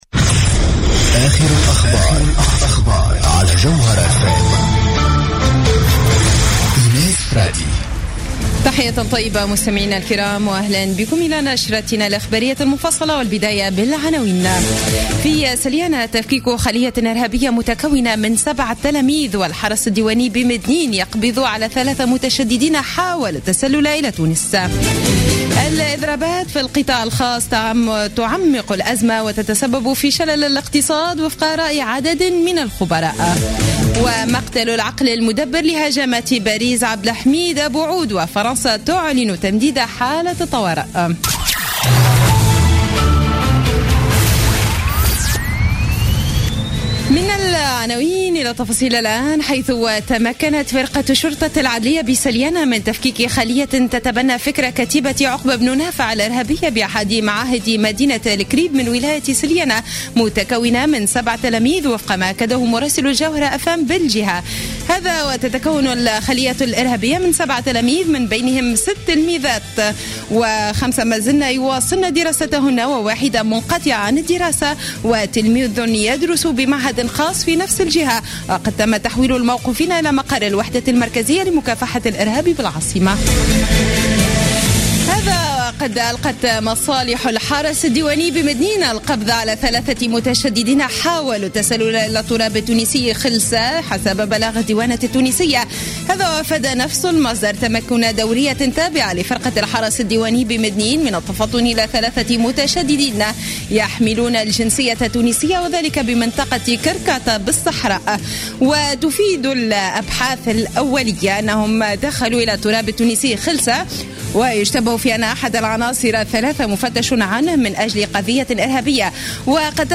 نشرة أخبار السابعة مساء ليوم الخميس 19 نوفمبر 2015